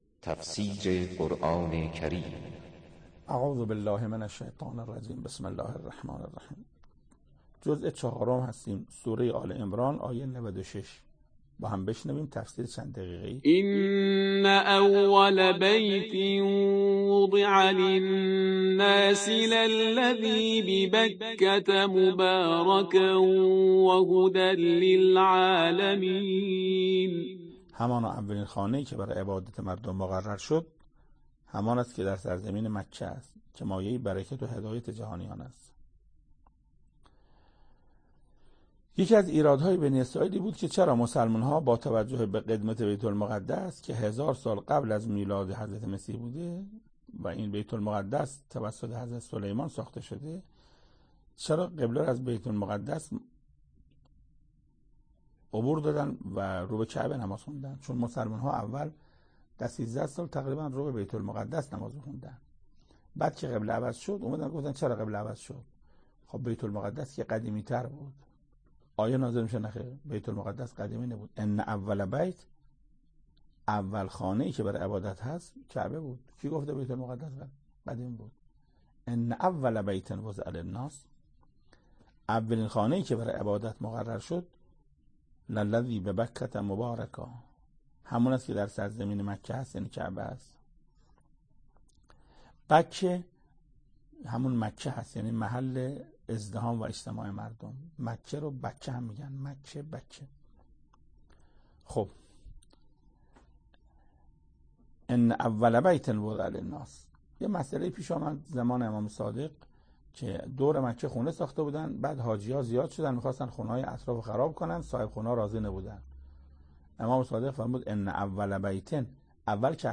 تفسیر نود و ششمین آیه از سوره مبارکه آل عمران توسط حجت الاسلام استاد محسن قرائتی به مدت 9 دقیقه